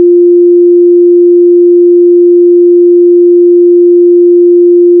Sirena electrónica
Tono 01 - Continuo 350Hz.
Tono 01 - Continuo 350Hz..wav